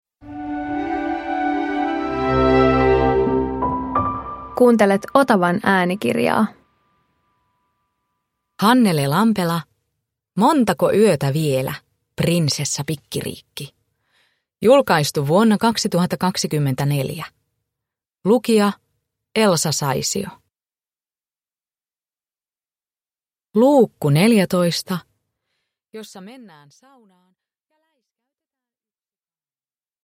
Montako yötä vielä, Prinsessa Pikkiriikki 14 – Ljudbok